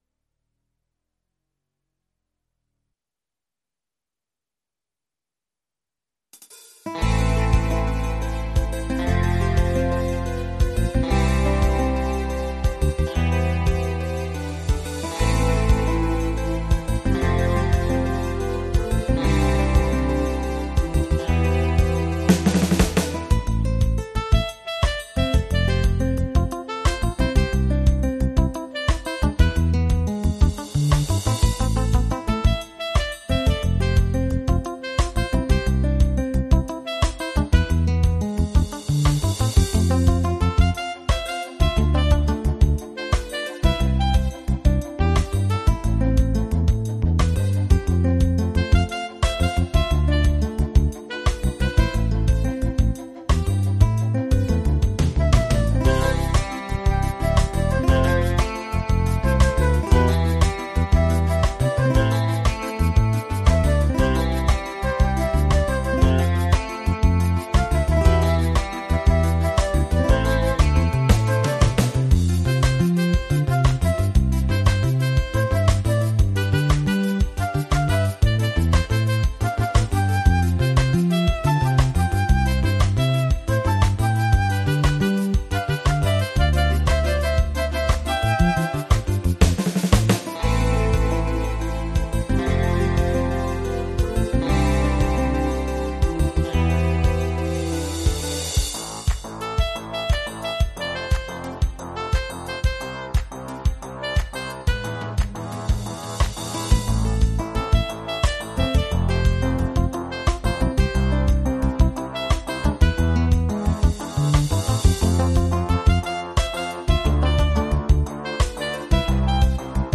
la version instrumentale multipistes de la chanson